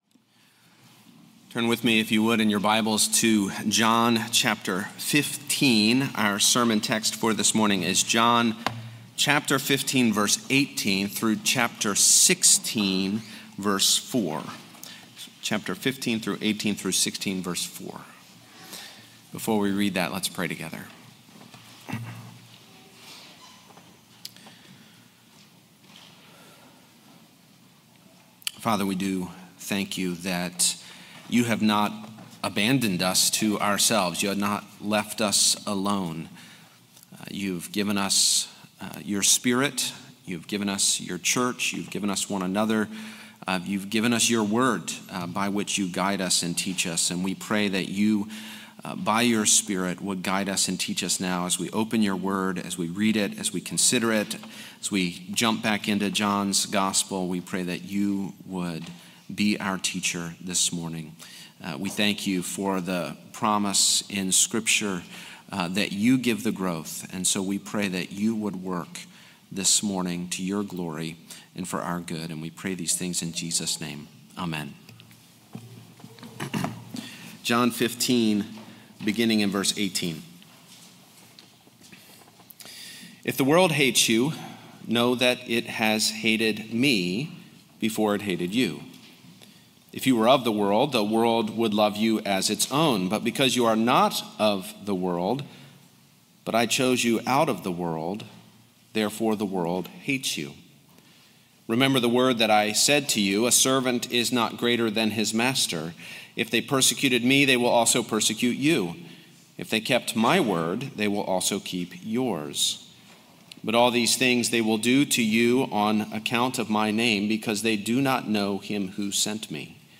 Weekly sermons from All Souls Presbyterian Church proclaim the good news of God's grace toward sinners through the loving sacrifice of Jesus Christ.